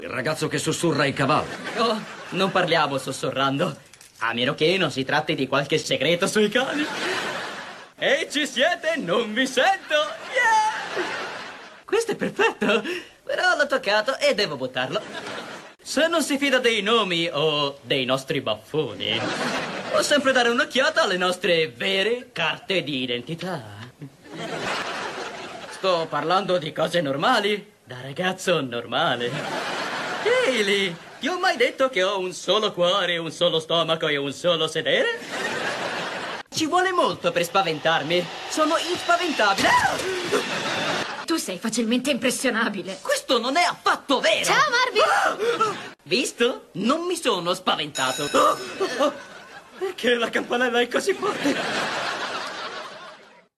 Marvin Marvin", in cui doppia Lucas Cruikshank.